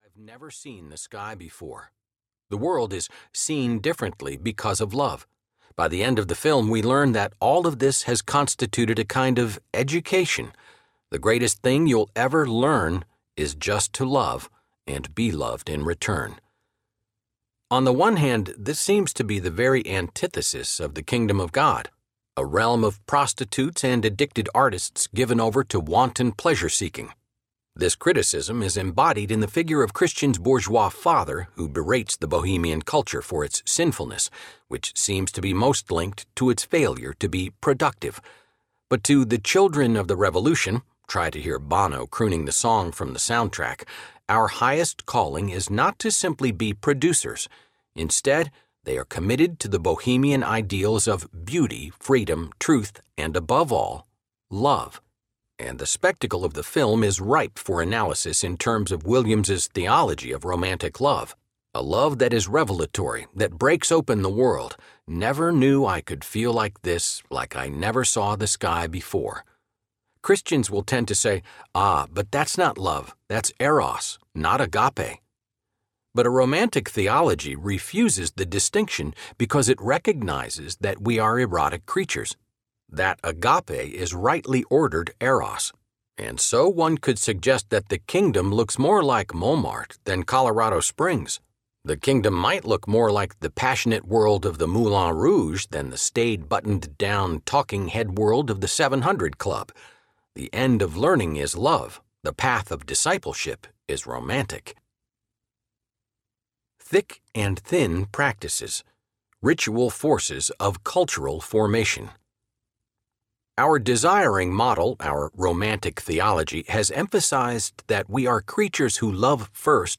Desiring the Kingdom (Cultural Liturgies, Volume #1) Audiobook
8.78 Hrs. – Unabridged